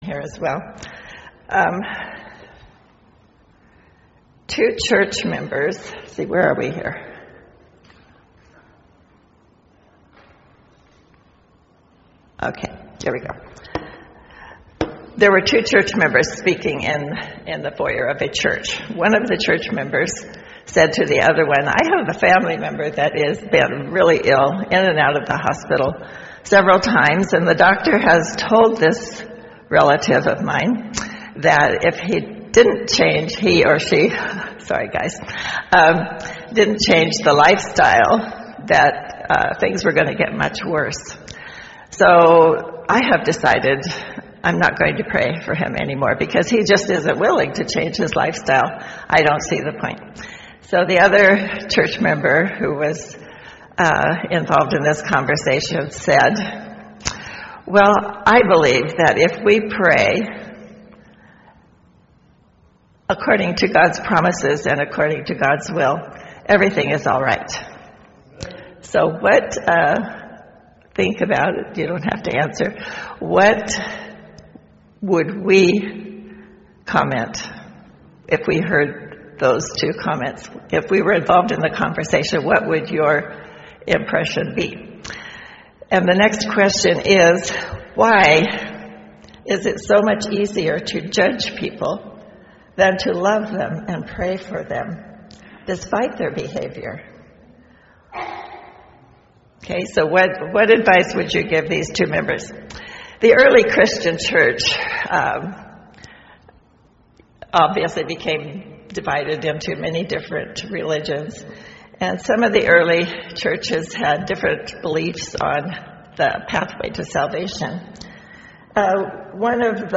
Sermons .
Arcata-McKinleyville Seventh-day Adventist Church McKinleyville, California